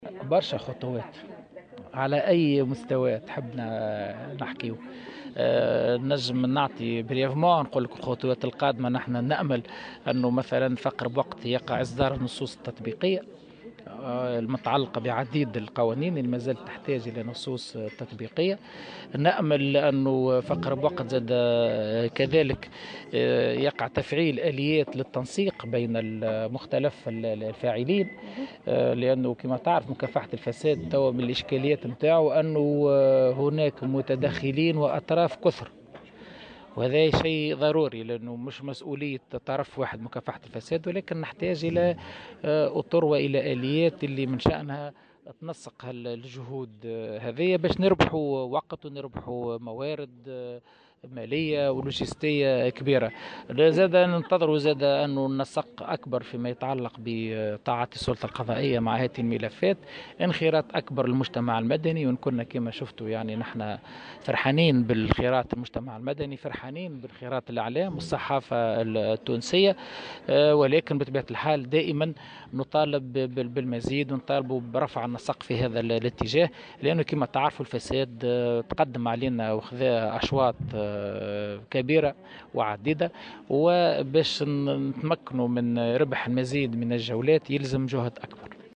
وأضاف في تصريح لمراسلة "الجوهرة أف أم" عل هامش إختتام أشغال المؤتمر الوطني لمكافحة الفساد " نحتاج إلى طرق وآليات لتنسيق مختلف الجهود لربح الوقت وموارد مالية ولوجستية.. ونأمل أن يتم في أقرب وقت إصدار النصوص التطبيقية وتفعيل آليات التنسيق بين مختلف الفاعلين".